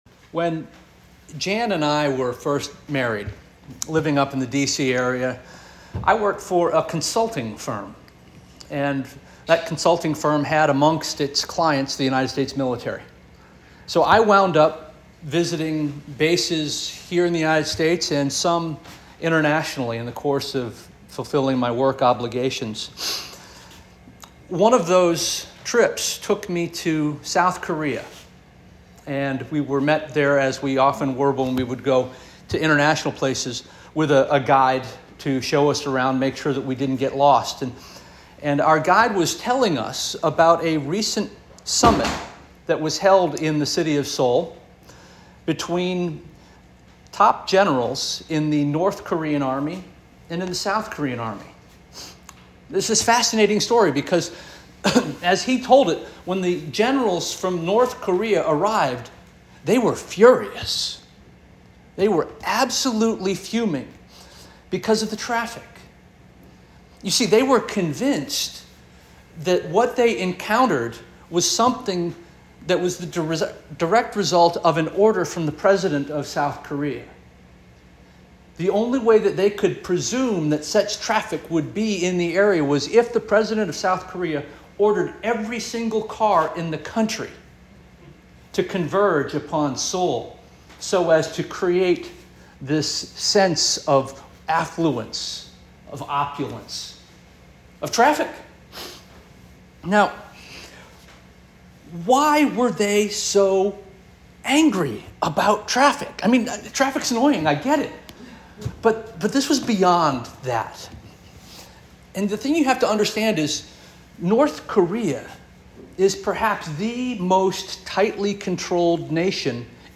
August 4 2024 Sermon - First Union African Baptist Church